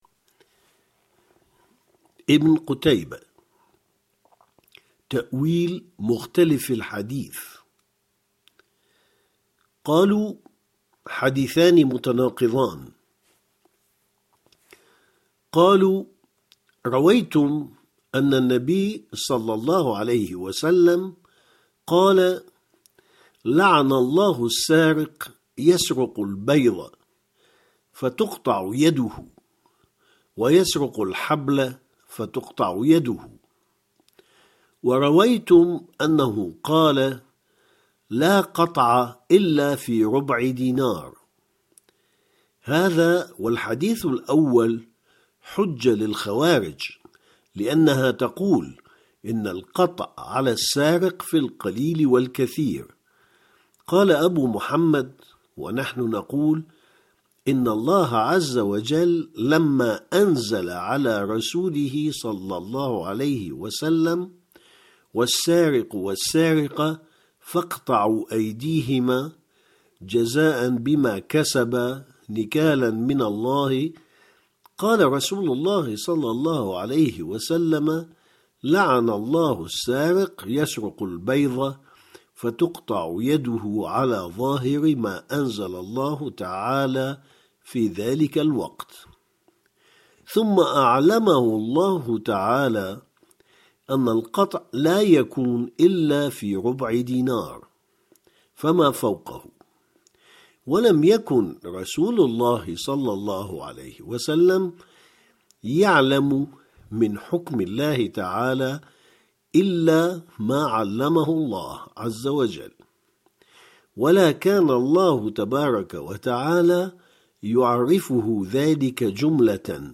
- Il se peut que des erreurs de prononciation se présentent dans les documenst sonores ; une version corrigée sera mise en ligne par la suite.